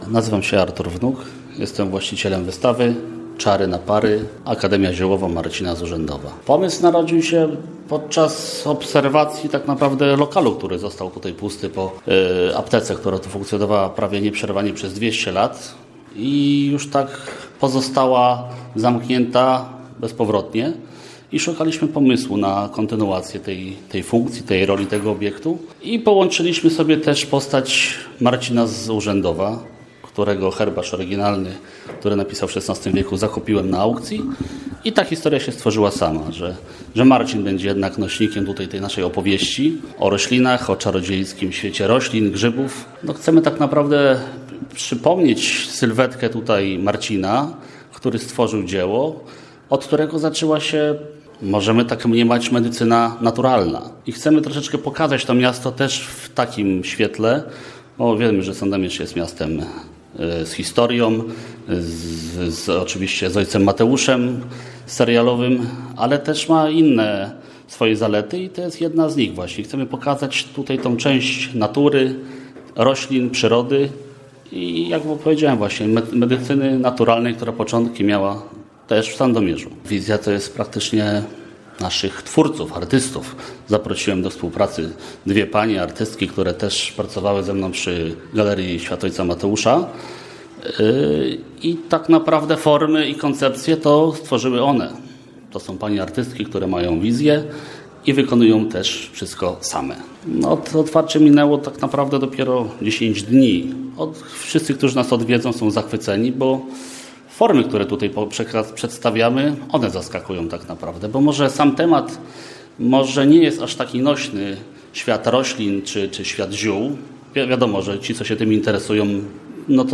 Radio Leliwa odwiedziło oryginalne muzeum mieszczące się przy sandomierskim rynku: